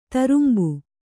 ♪ tarumbu